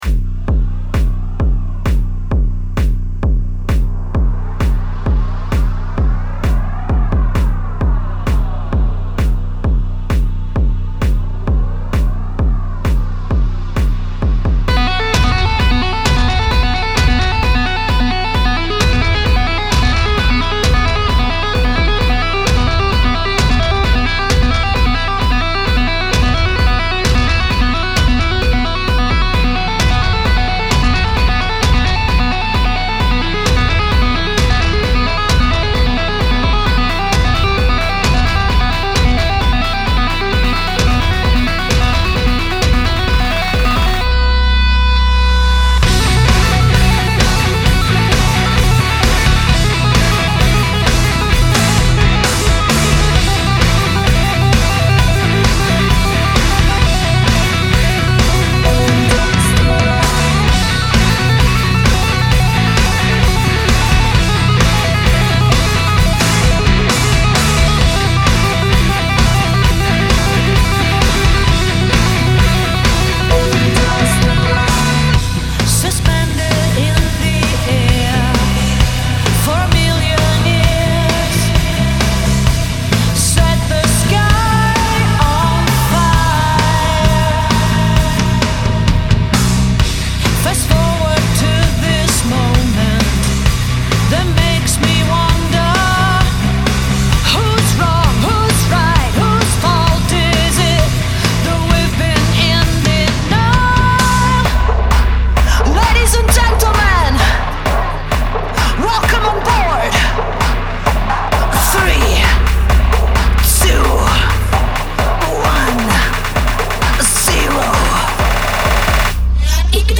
Vocals
Guitars, Bass, Keyboard, Programming
Drums